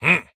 Minecraft Version Minecraft Version 1.21.5 Latest Release | Latest Snapshot 1.21.5 / assets / minecraft / sounds / mob / vindication_illager / idle2.ogg Compare With Compare With Latest Release | Latest Snapshot